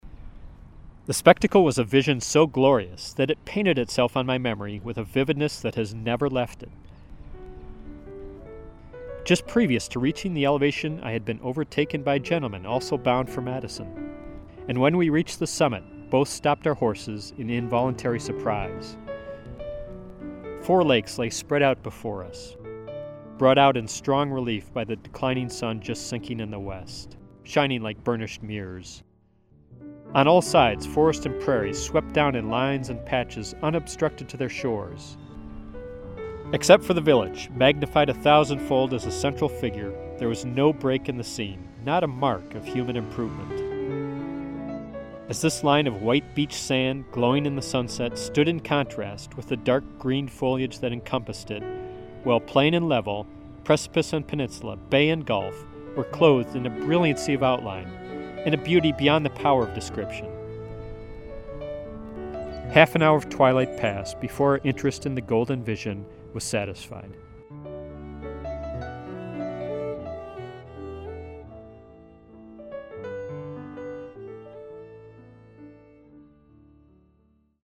Note: the background music, “Late Summer Air,” was used in the wonderful 6-part National Parks documentary by Ken Burns and Dayton Duncan, in which hometown historian Bill Cronon figures prominently.